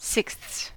/sɪksθs/), anesthetic (/ˌænəsˈθɛtɪk/), etc., are commonly very difficult for foreign learners to pronounce.
En-us-sixths.ogg.mp3